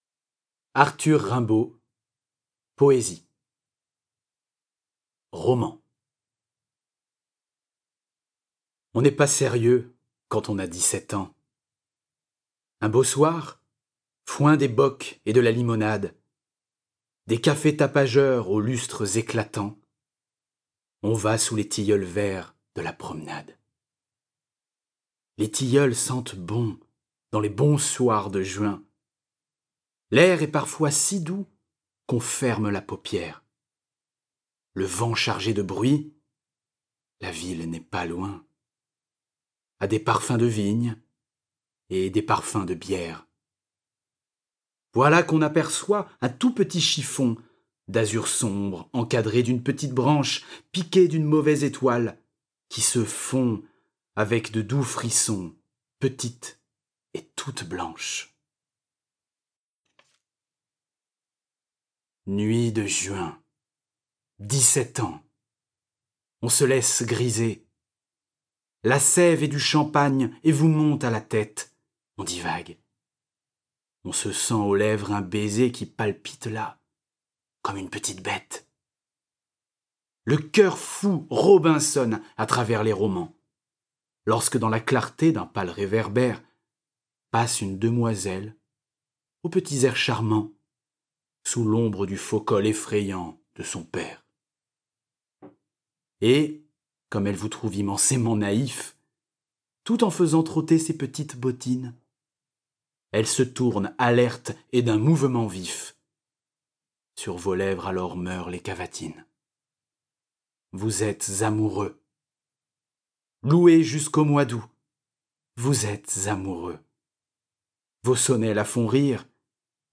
L’art de la récitation